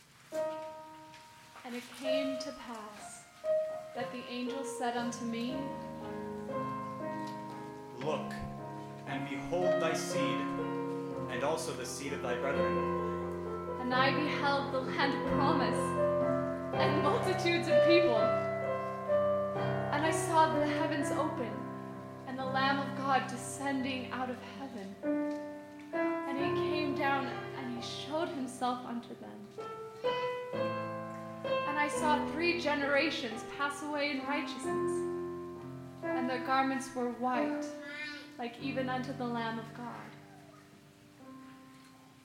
Soprano, Tenor, and Piano